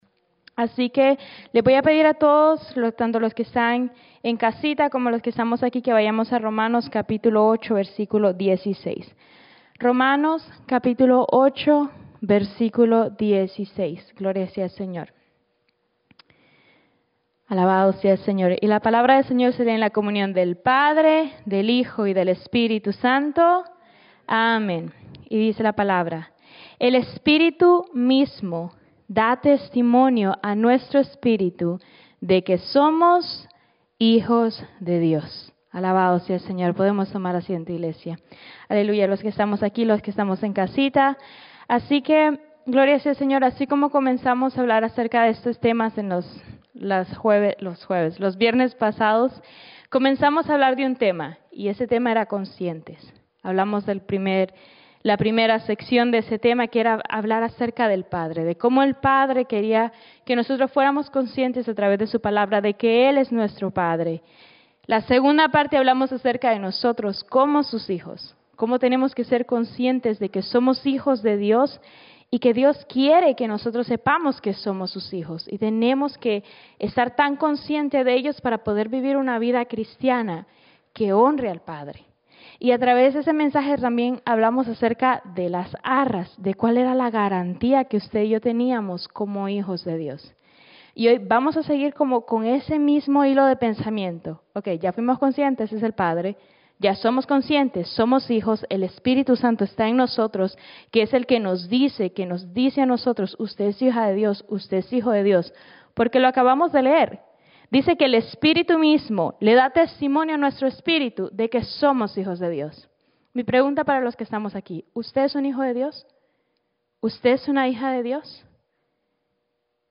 Mensaje